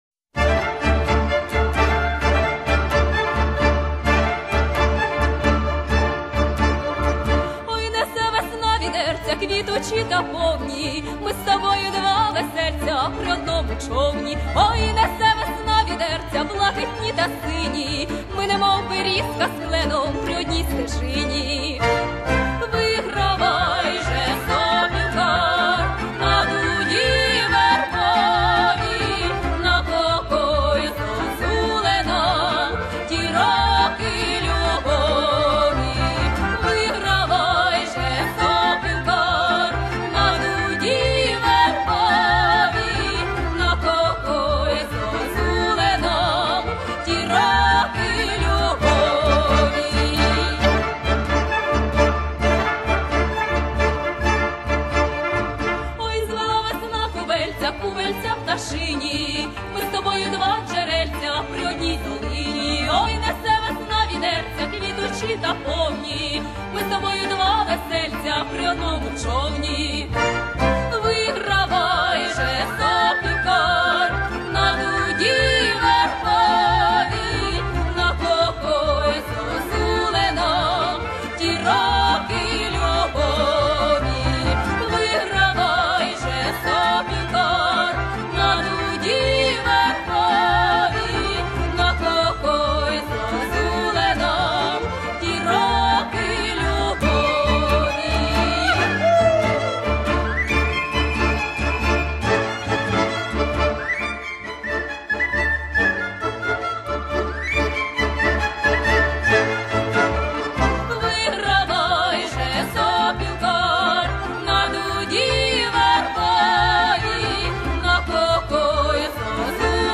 » - Ensemble ukrainien de musique traditionnelle